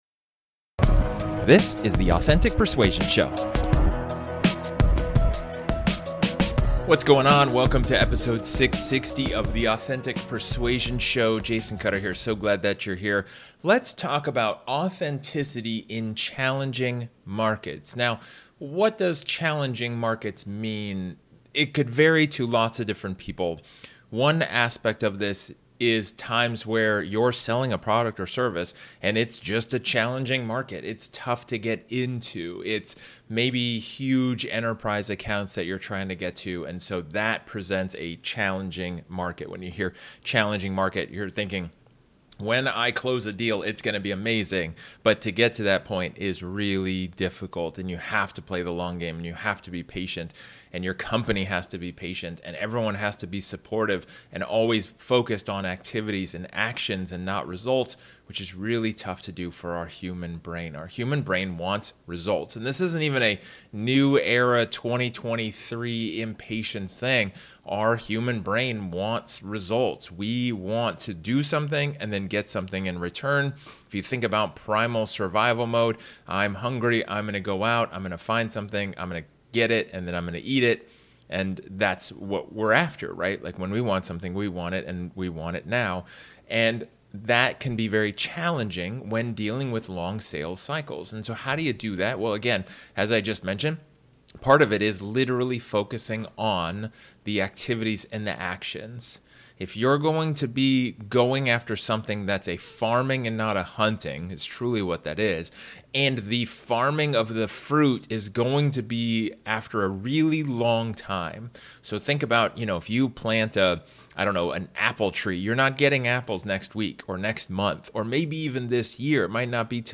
In this solo episode, I talk about Authenticity in Challenging Markets.